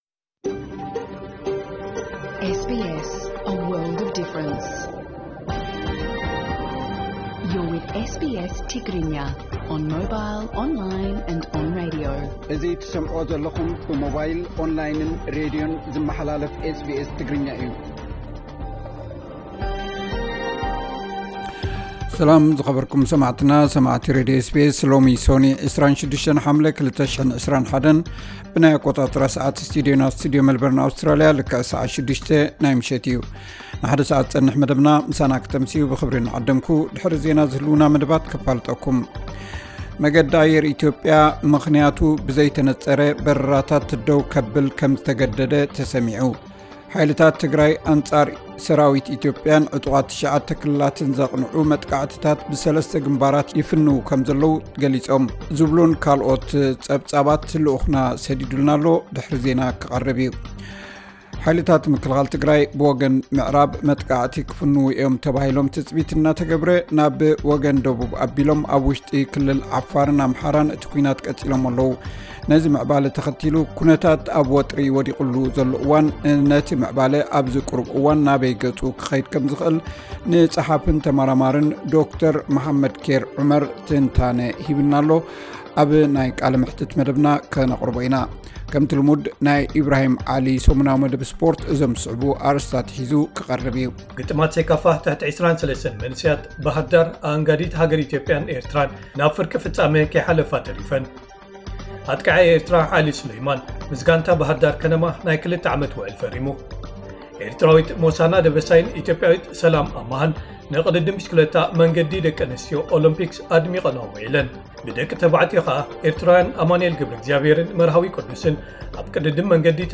ዕለታዊ ዜና 26 ሓምለ 2021 SBS ትግርኛ